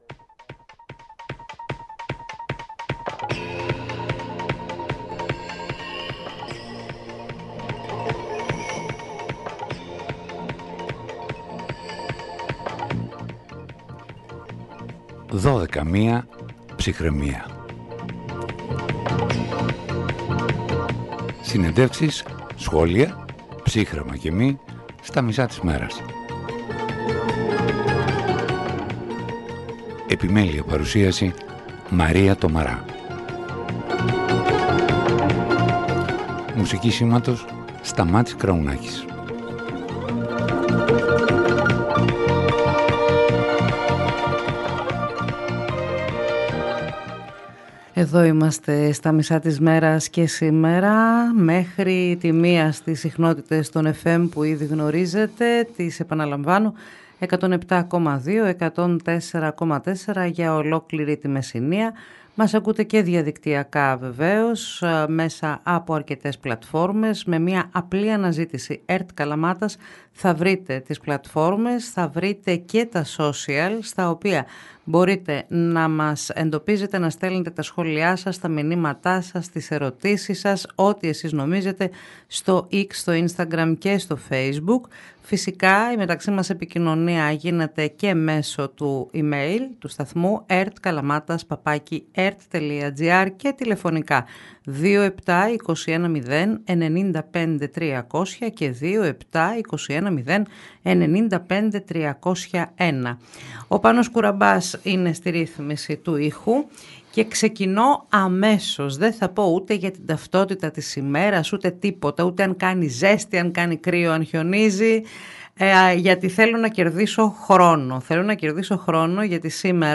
στο ραδιόφωνο της ΕΡΤ Καλαμάτας